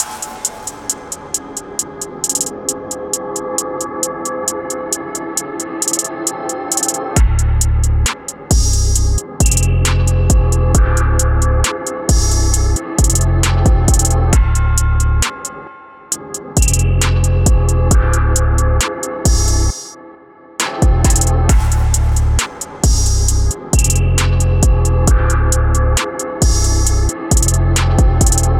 Beat snippet